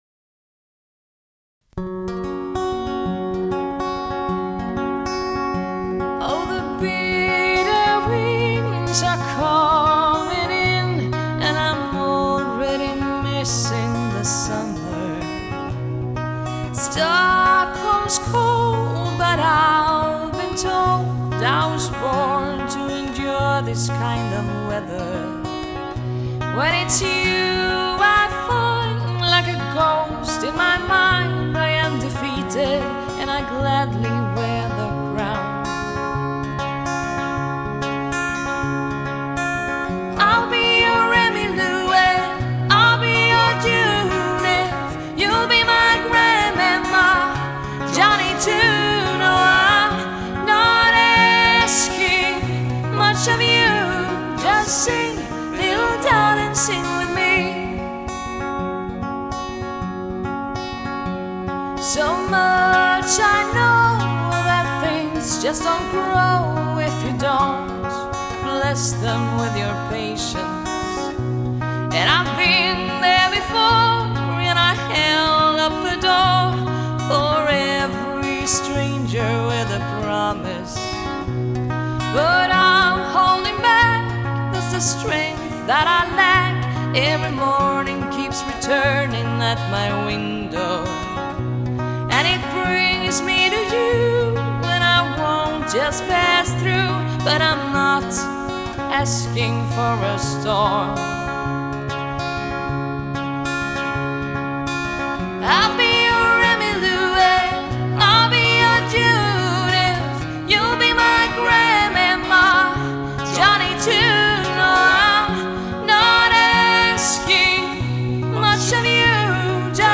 • Coverband
• Duo/trio